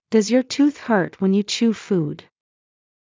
ﾀﾞｽﾞ ﾕｱ ﾄｩｰｽ ﾊｰﾄ ｳｪﾝ ﾕｳ ﾁｭｳ ﾌｰﾄﾞ